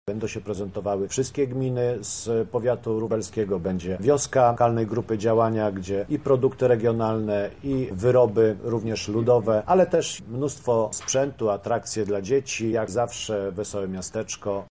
O innych atrakcjach mówi Mirosław Żydek, wójt gminy Konopnica :